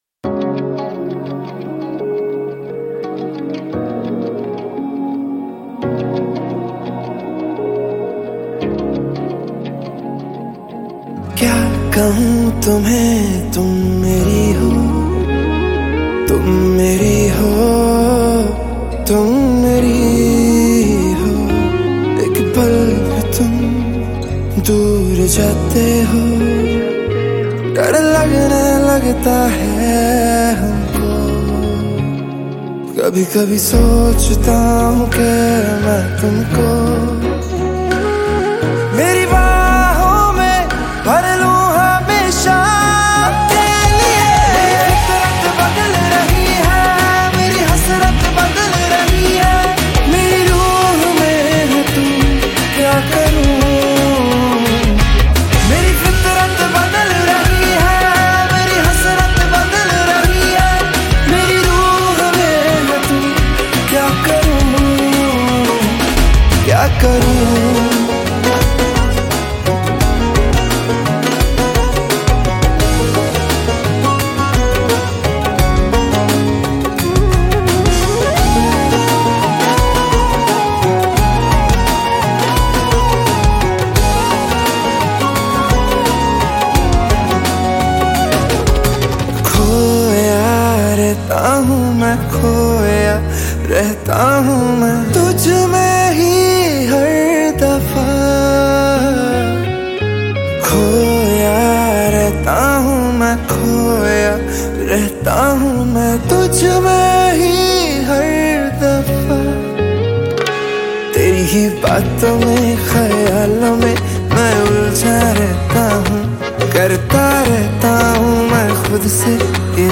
Punjabi Bhangra MP3 Songs
Indian Pop